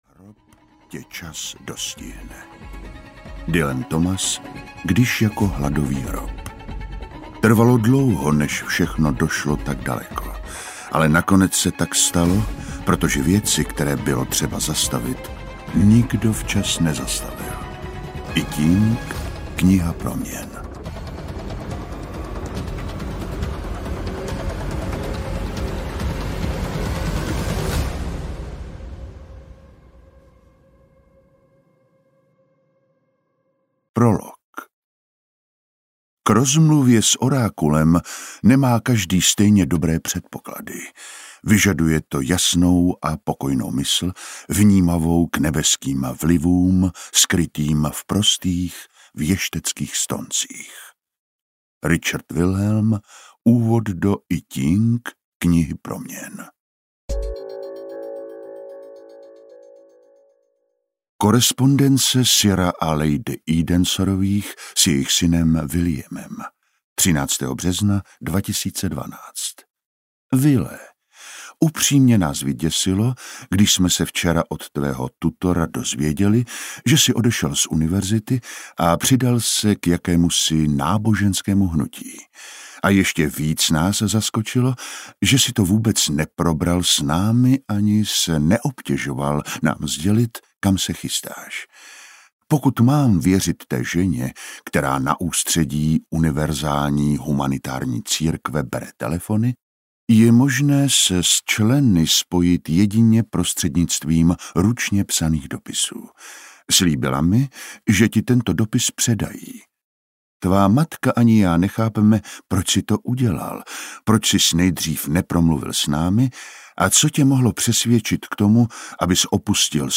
Hladový hrob audiokniha
Ukázka z knihy